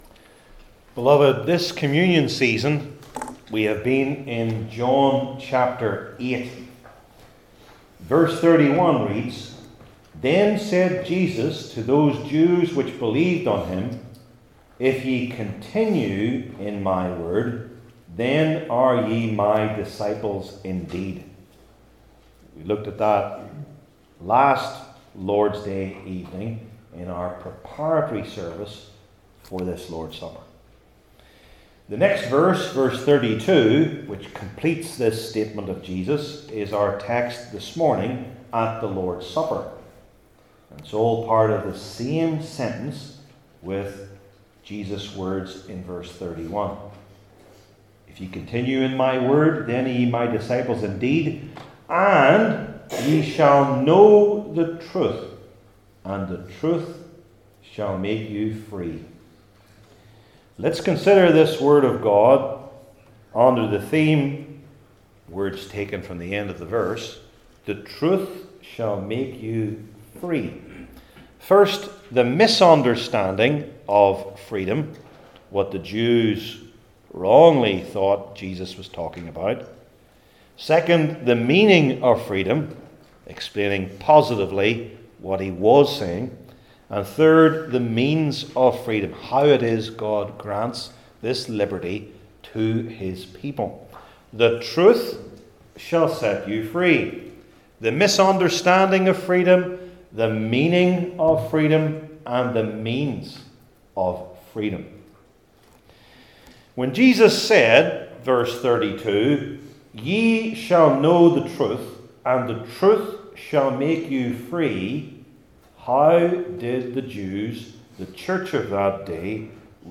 New Testament Individual Sermons I. The Misunderstandings of Freedom II.